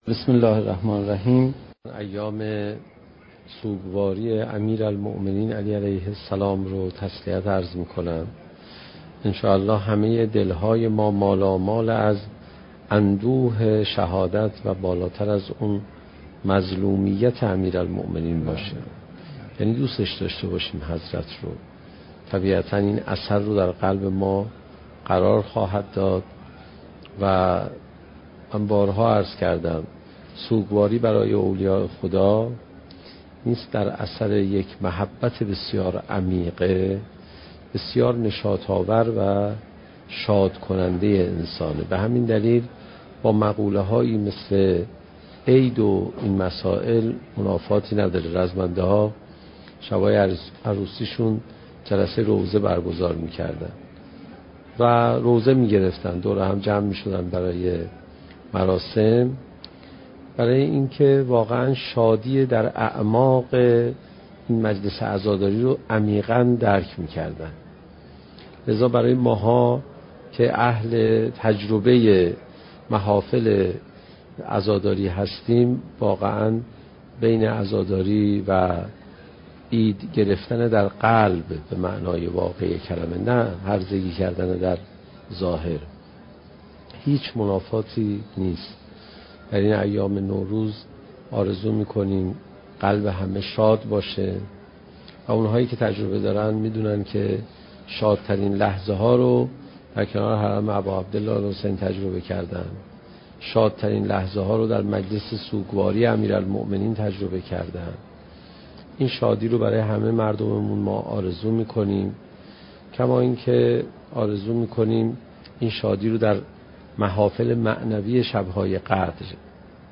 سخنرانی حجت الاسلام علیرضا پناهیان با موضوع "چگونه بهتر قرآن بخوانیم؟"؛ جلسه هفدهم: "اهمیت تفکر بهتر در قرآن"